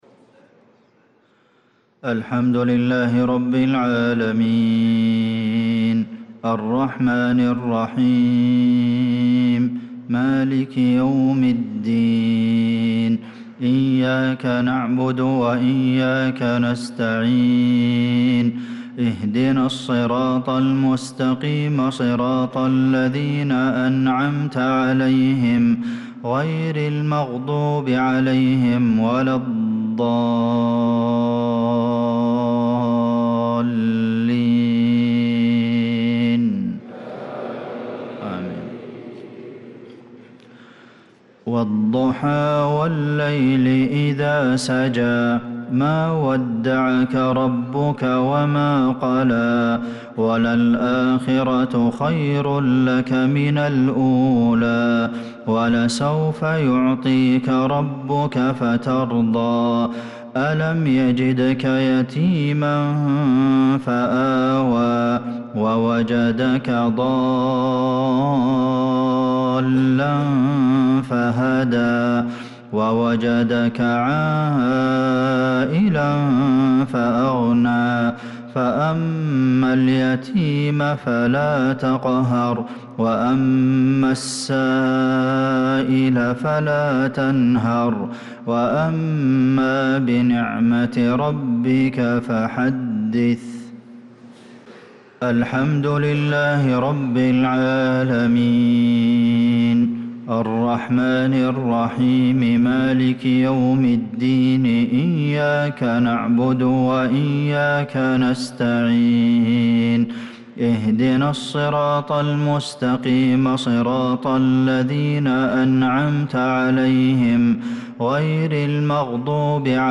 صلاة المغرب للقارئ عبدالمحسن القاسم 5 شوال 1445 هـ